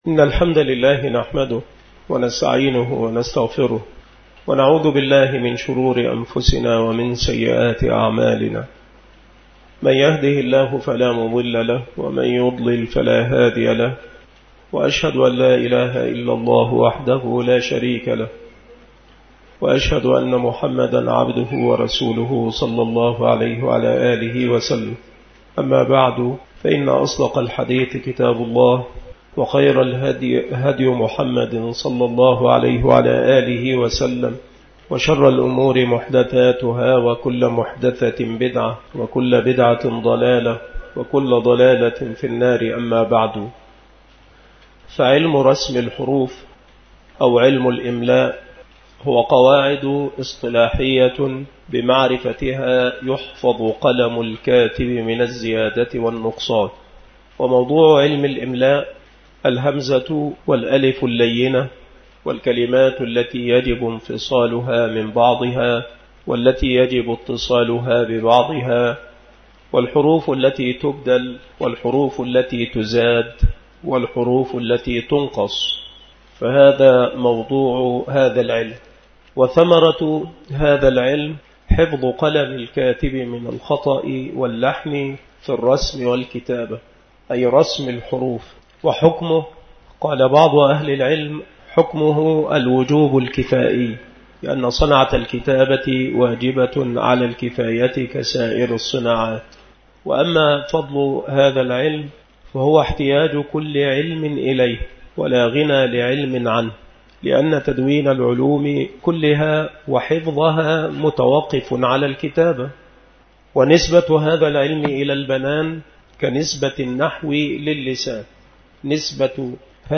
من دروس الدورة العلمية التمهيدية لمعهد الفرقان لإعداد الدعاة بالمسجد الشرقي بسبك الأحد - أشمون - محافظة المنوفية - مصر